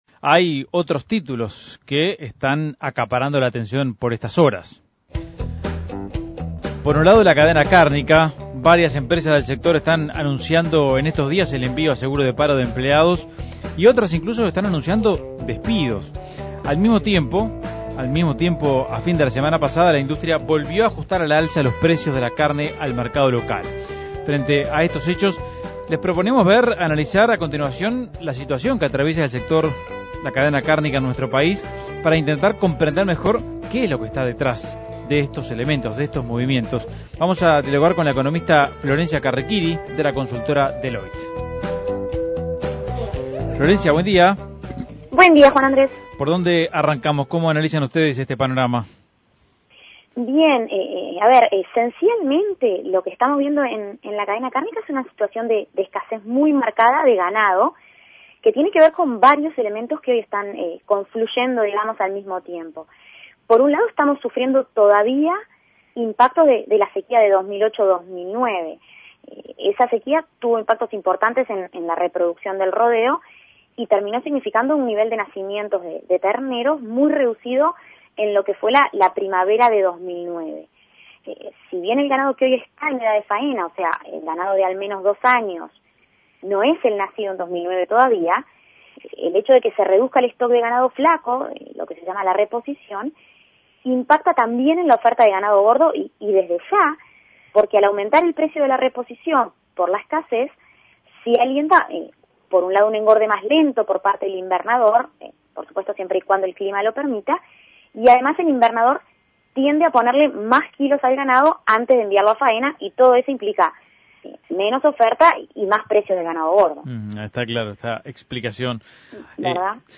Análisis Económico Los precios del ganado alcanzan nuevos récords y la carne no cesa de subir.